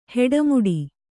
♪ heḍa muḍi